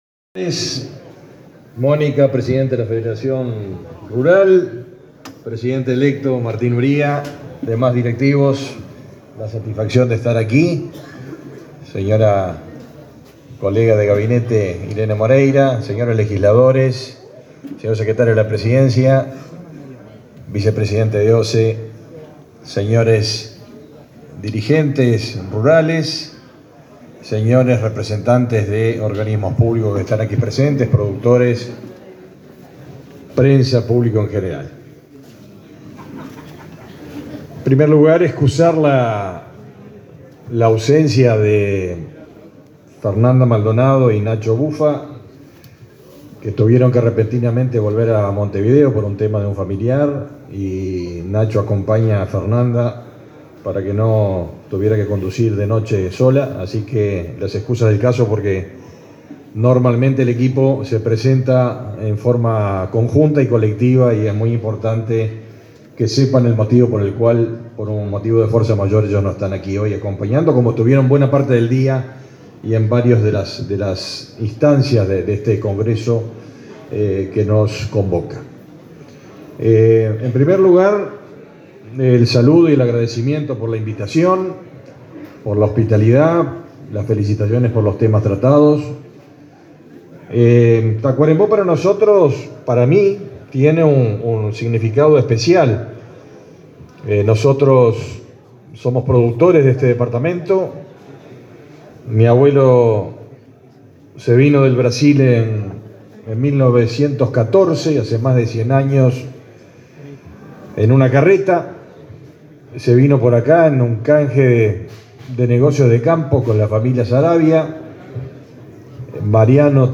Palabras del ministro de Ganadería, Agricultura y Pesca, Fernando Mattos
El presidente de la República, Luis Lacalle Pou, participó, este 28 de mayo, en la clausura del 105.º Congreso de la Federación Rural, en Tacuarembó.
En el evento, también brindó una conferencia el ministro Fernando Mattos.